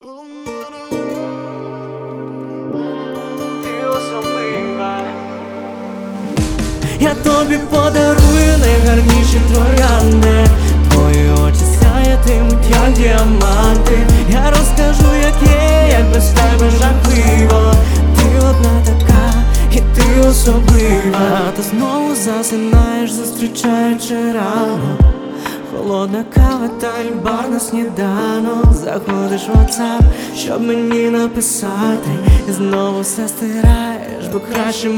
Жанр: Поп / Русские